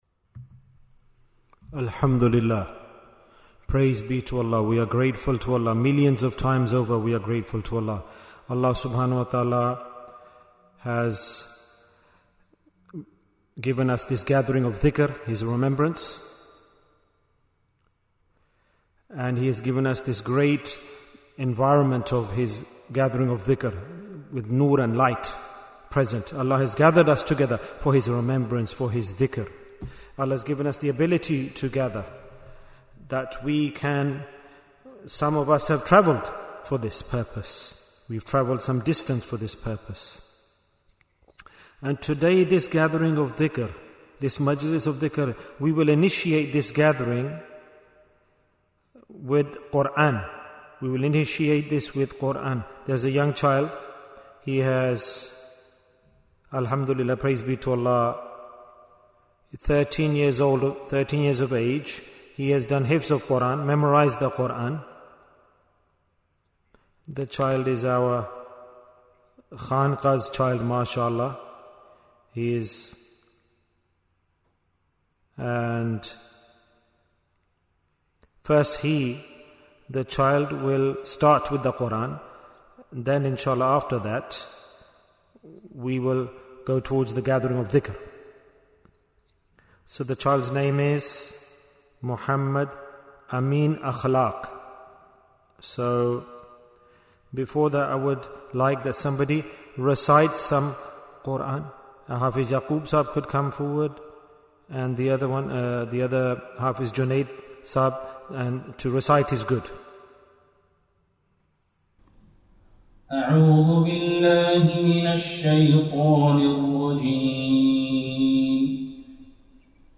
Dhikr Majlis Manchester Bayan, 33 minutes24th August, 2025